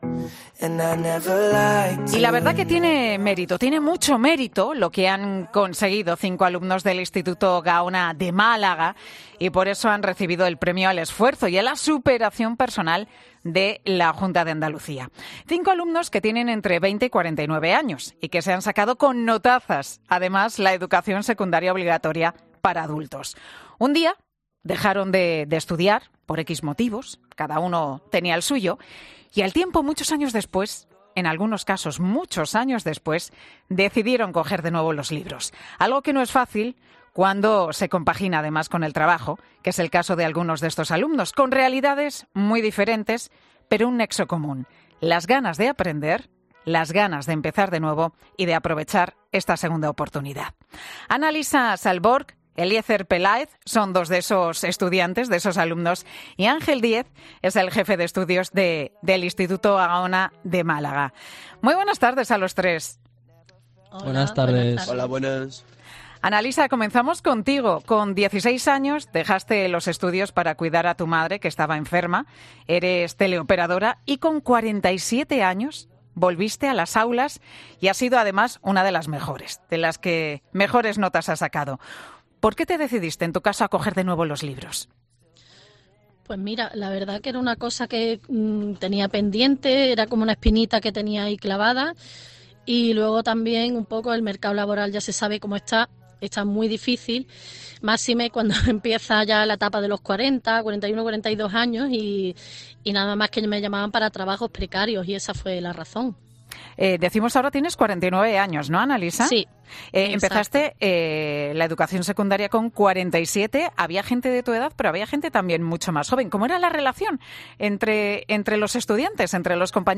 Los alumnos honrados por sus notas en la ESO de adultos de un Instituto de Málaga en 'Mediodía COPE'